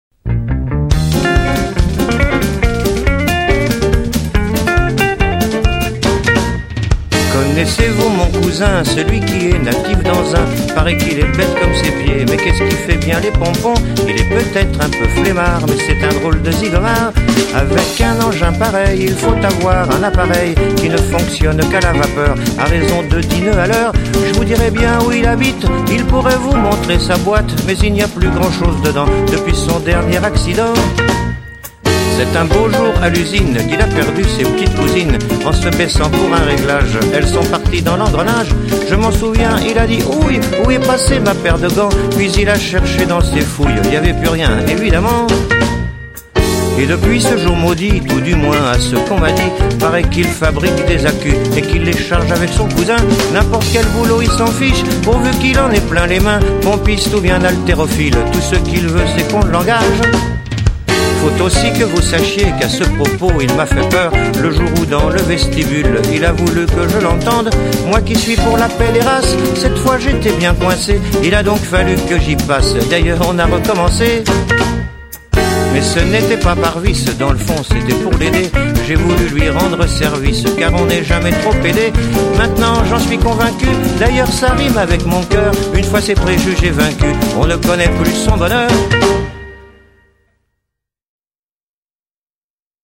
marqué par un swing manouche indéniable